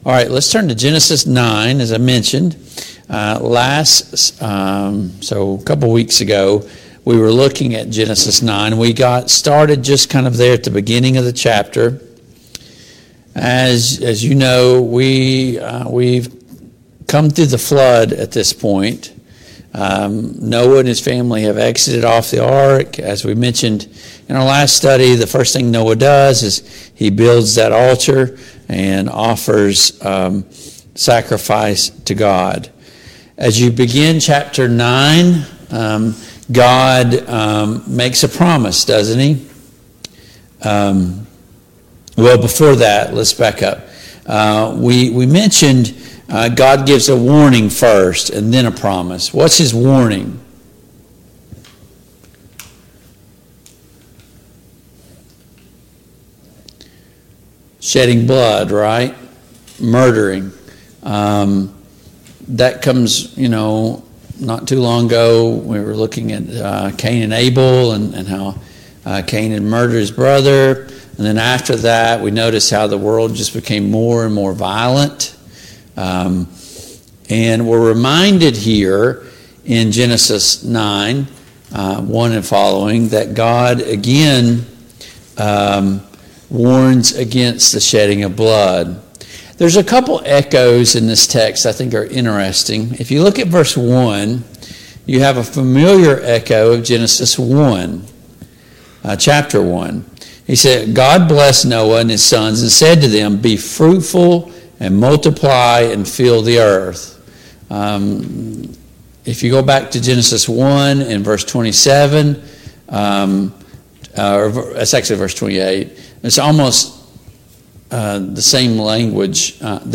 Genesis 10 Service Type: Family Bible Hour Topics: Noah , Noah's Desendants , The Flood « What are the characteristics of a living faith? 23.